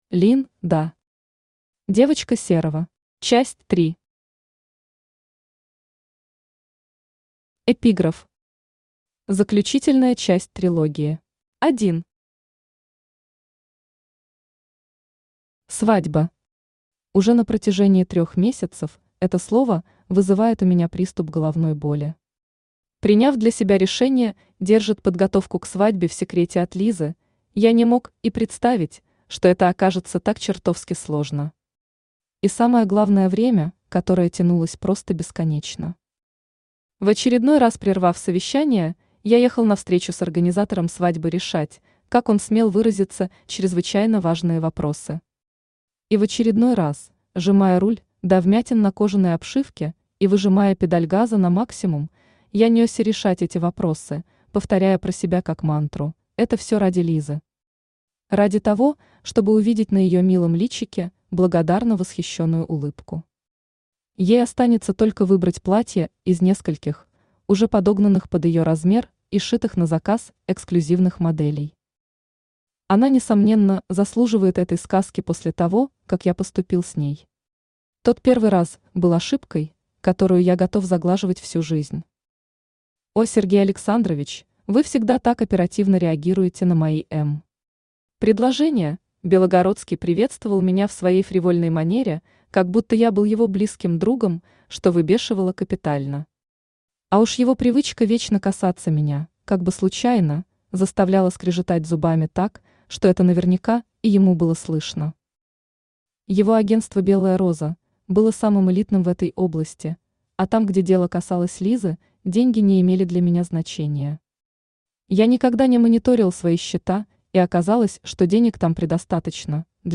Часть 3 Автор Лин Да Читает аудиокнигу Авточтец ЛитРес.